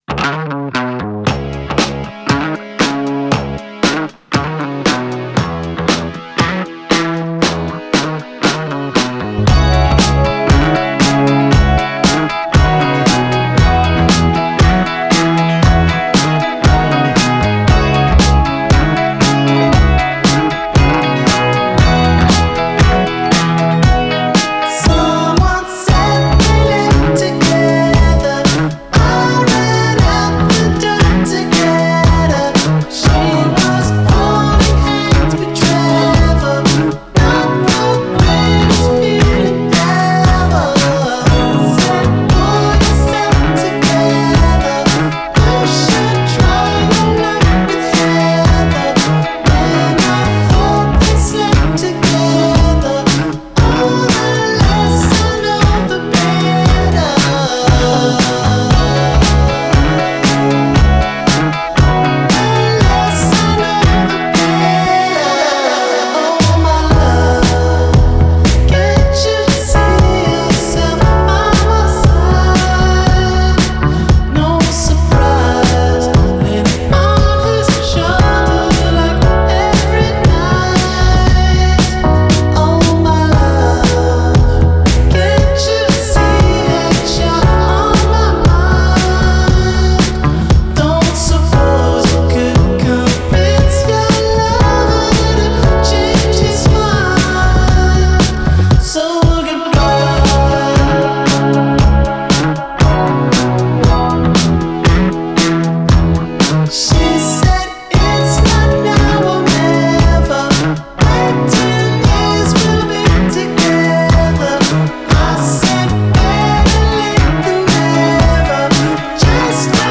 Genre: Indie Rock, Psychedelic